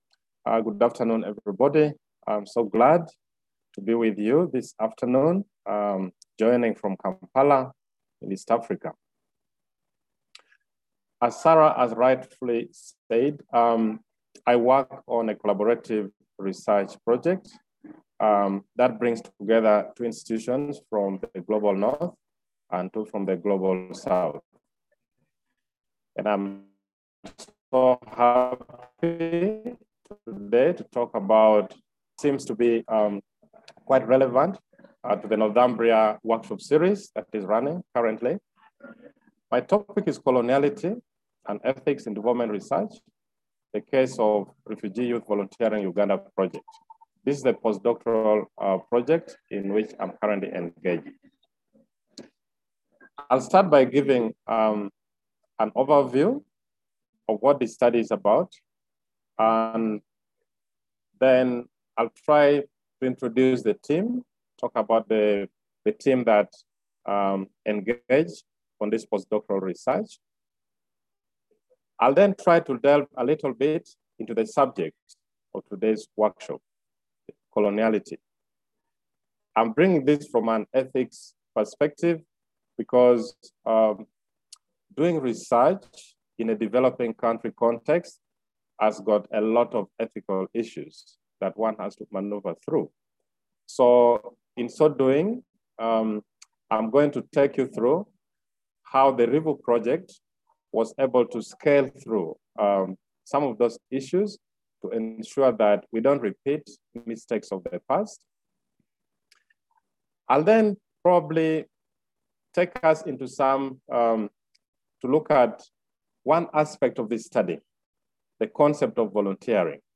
La presentación busca abrir un espacio para un mayor debate sobre las políticas institucionales de investigación, los modelos de financiación y la inclusión de la agenda de investigación. Sobre la presentación (que fue seguida por una discusión participativa): Sobre el ponente